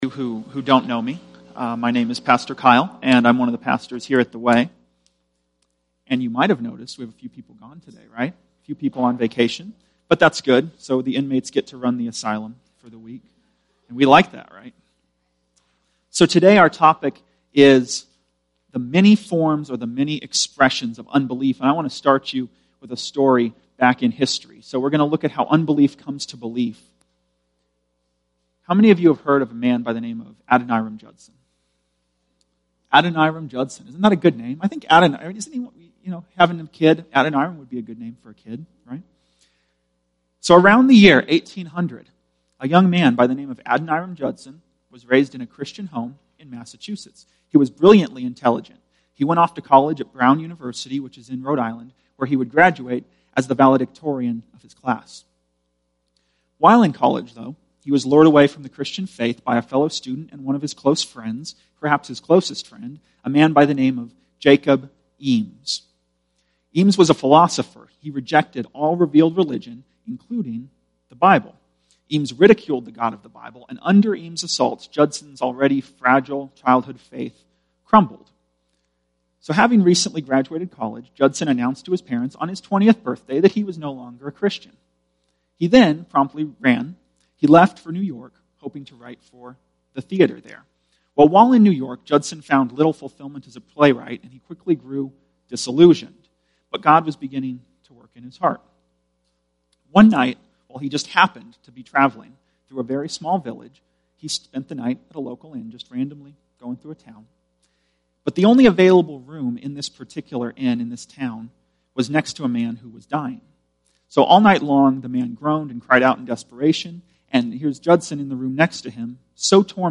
Sunday Worship
Tagged with Sunday Sermons Audio (MP3) 14 MB Previous Matthew 12:22-37: Stop Resisting the Holy Spirit Next Matthew 13:1-23: To Bear and Yield Fruit